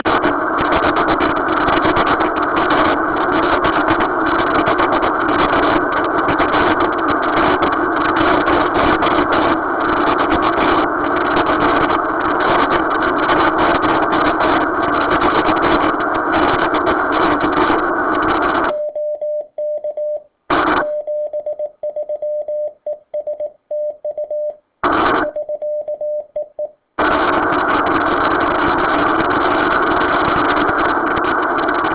The following table has some recordings I made from the event.